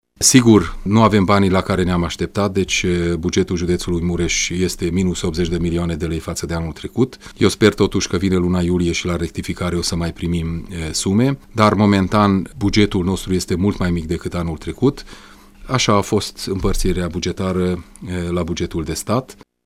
Bugetul alocat județului Mureș pentru 2018 este mai mic cu 80 de milioane de lei față de cel de anul trecut, a declarat azi la Radio Tg.Mureș, președintele CJ Mureș Peter Ferenc.
Ferenc-buget.mp3